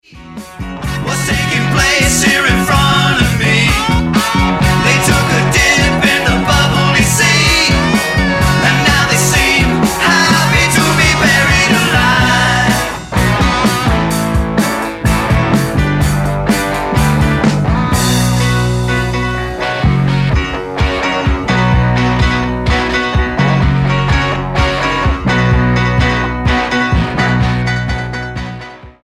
STYLE: Jesus Music
mid '70s pop rock